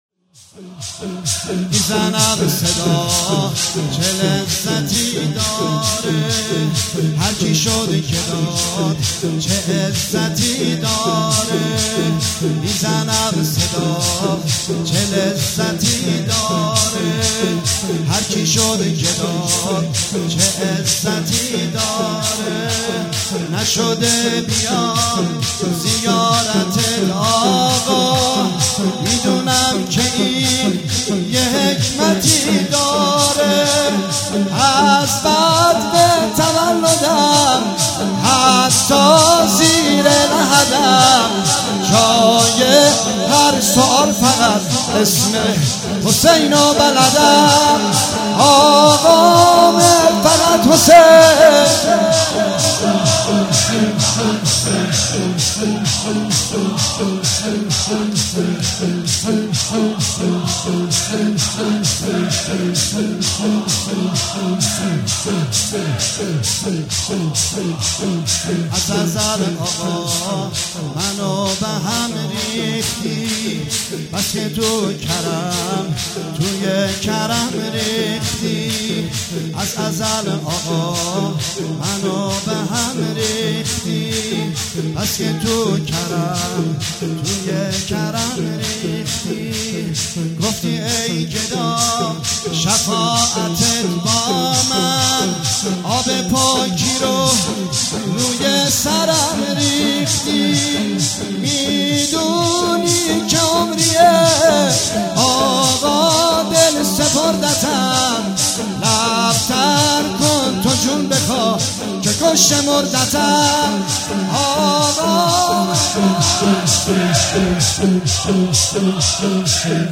شب اول محرم سال 95/هیت رزمندگان مکتب الحسین(ع)